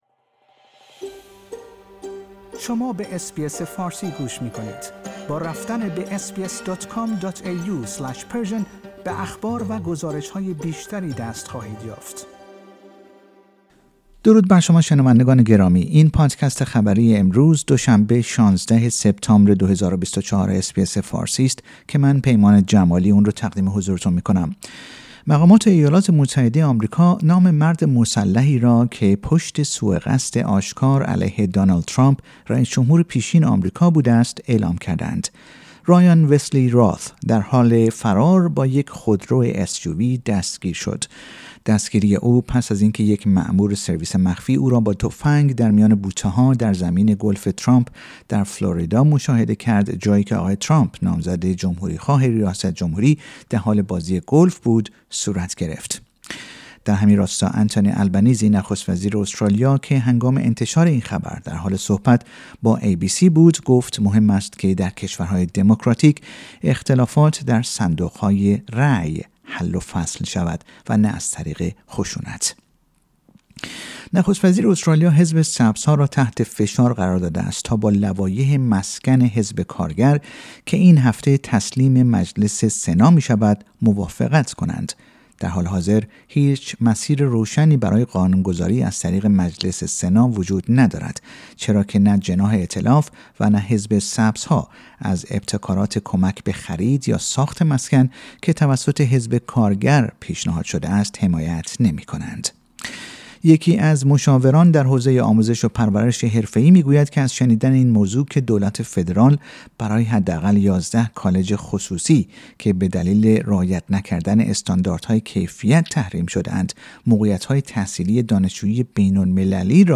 در این پادکست خبری مهمترین اخبار استرالیا در روز دوشنبه ۱۶ سپتامبر ۲۰۲۴ ارائه شده است.